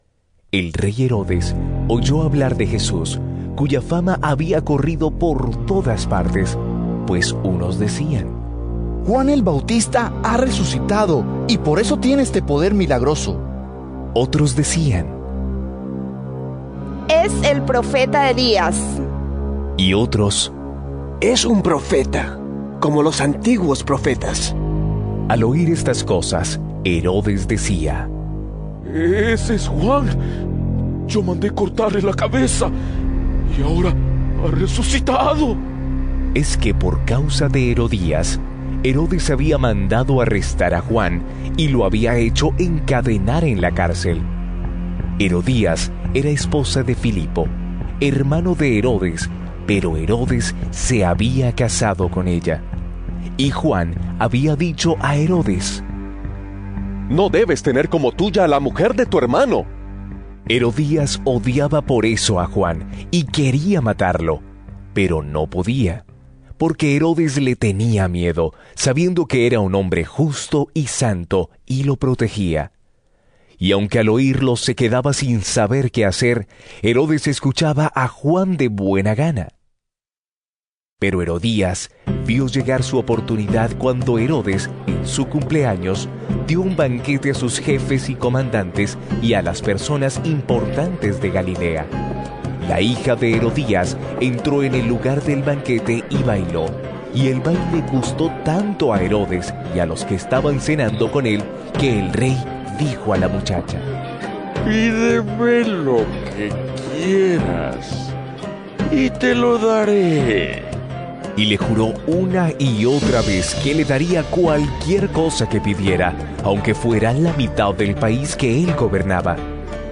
LECTURA del Evangelio según Marcos 6, 14-29